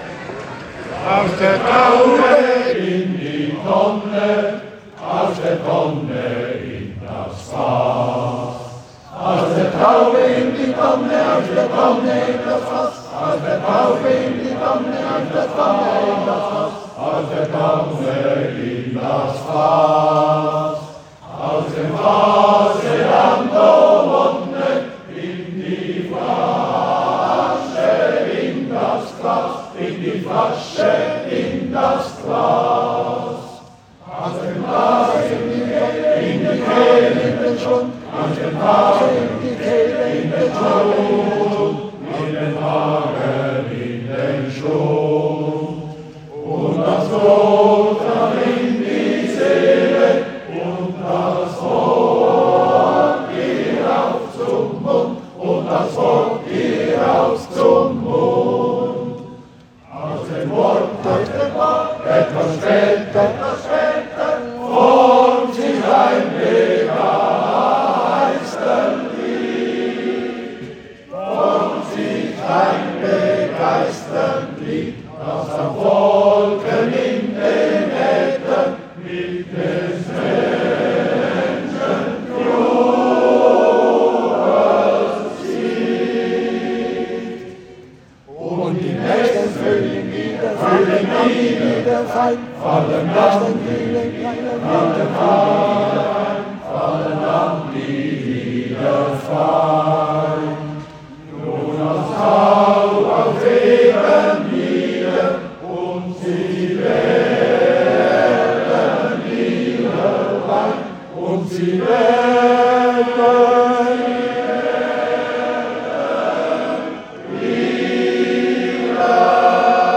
Kantonales Gesangsfest, 24. Mai, 2025
Insgesamt nahmen 48 Chöre daran teil.
Das Lied «Freude am Leben» wurde auswendig vorgetragen, während die Sänger für den Männerchor Blues die Noten in den Händen hielten. Am Ende des Vortrages applaudierten die Zuhörer/innen im gut besetzten Konzertsaal frenetisch.
Lied wurden die Sänger von ihrem Dirigenten am Klavier begleitet, wofür er vom Experten speziell gelobt wurde.